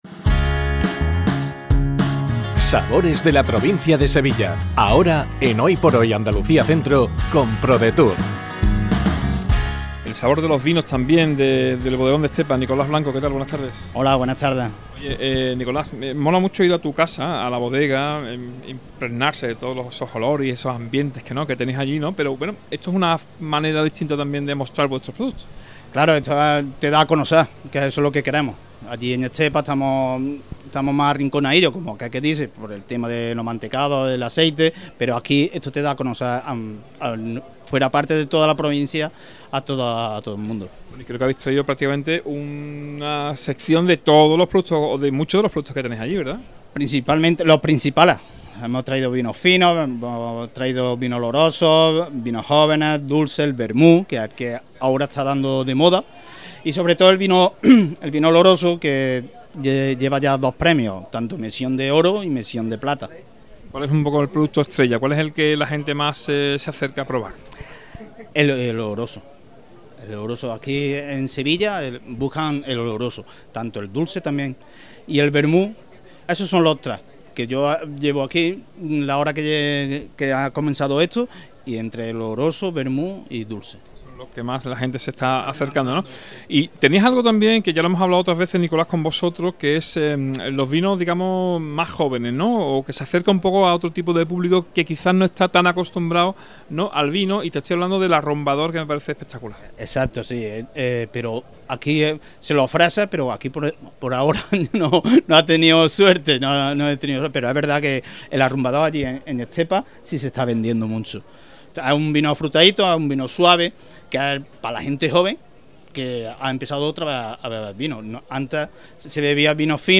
ENTREVISTA | EL BODEGON